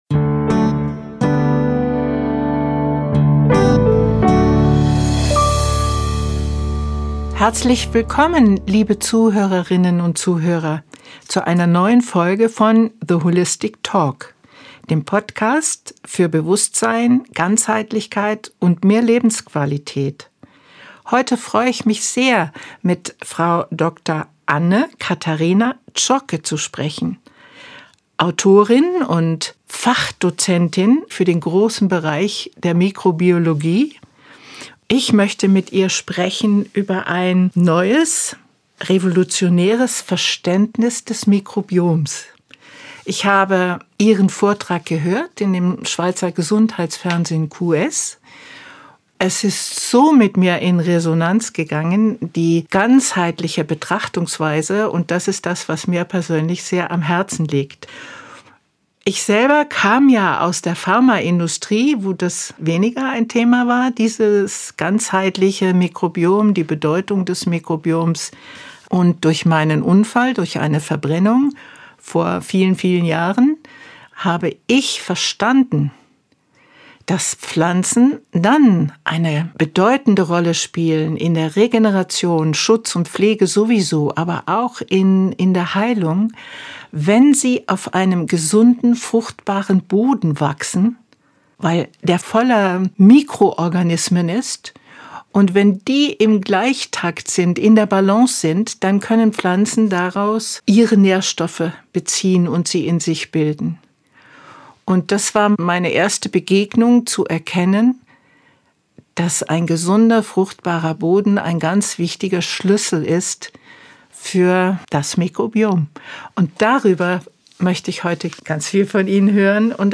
Es ist ein Gespräch, das tief berührt und unser Bild von Gesundheit auf den Kopf stellt: Bakterien sind keine Feinde, sondern unsere engsten Verbündeten.